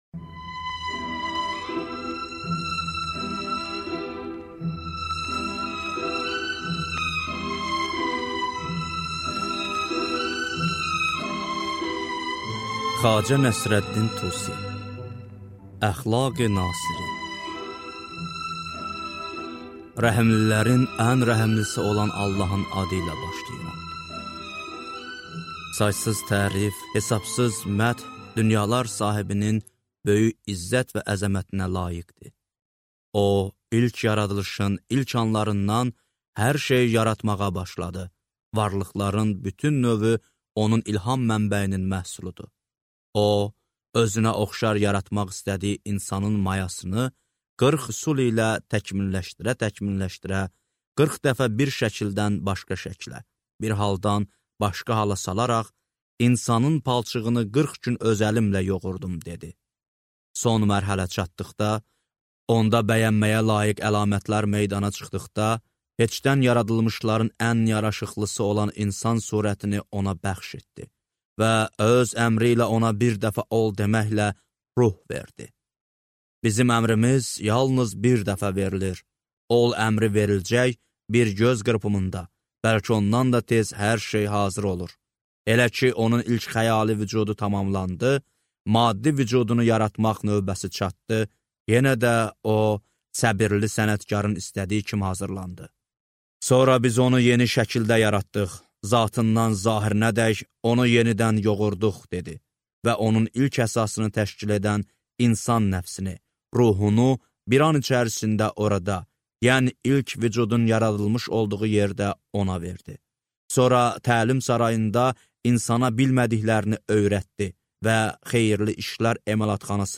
Аудиокнига Əxlaqi nasiri | Библиотека аудиокниг
Читает аудиокнигу